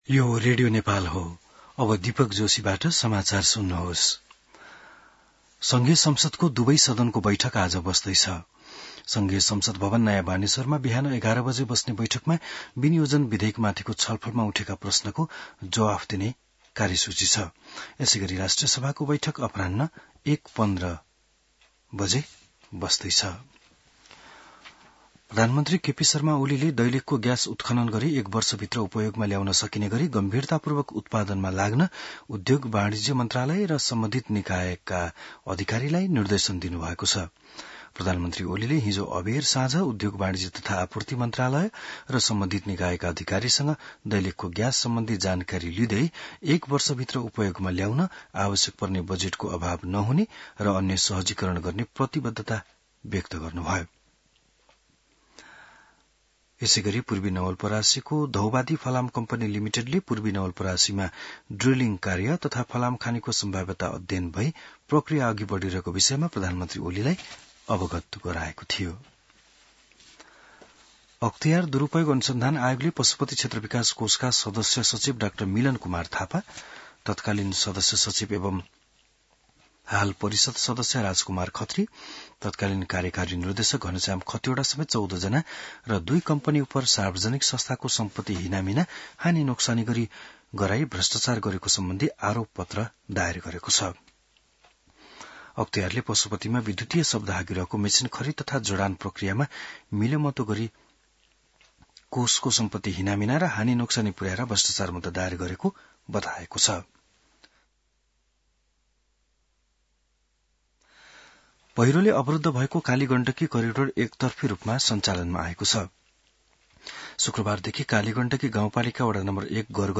बिहान १० बजेको नेपाली समाचार : ९ असार , २०८२